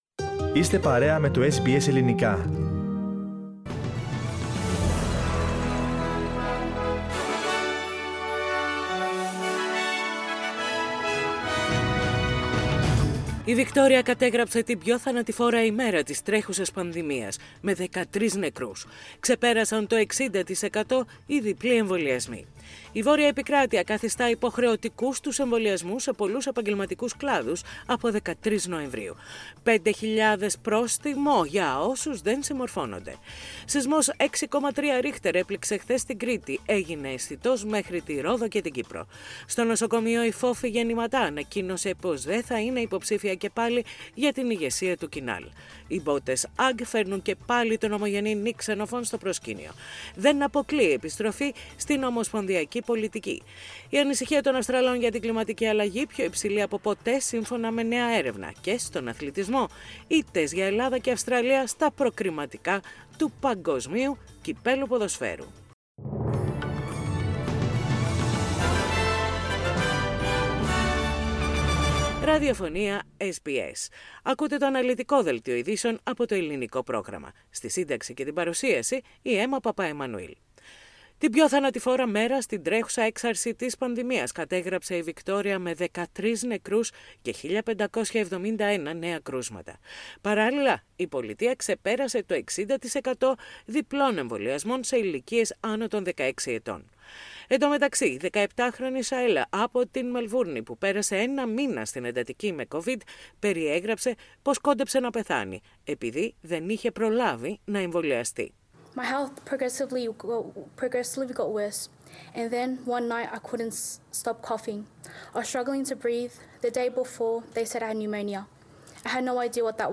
Δελτίο ειδήσεων - Τετάρτη 13.10.21